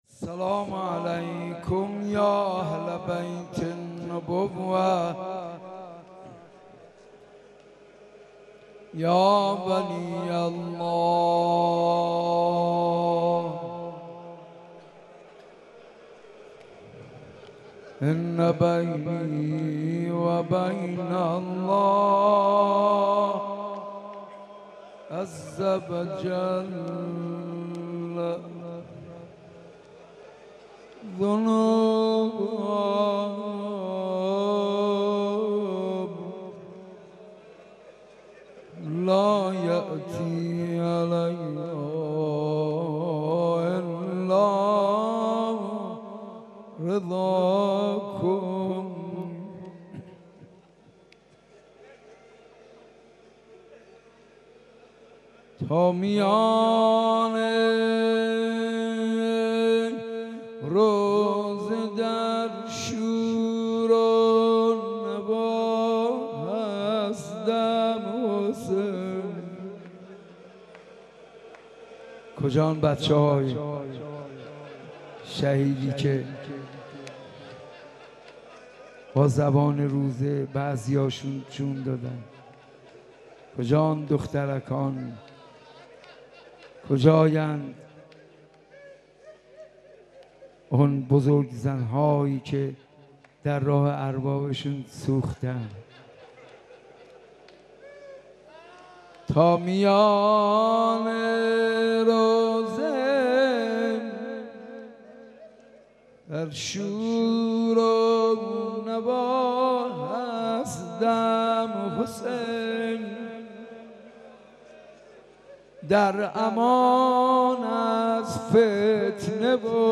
مناسبت : شب پنجم محرم
قالب : روضه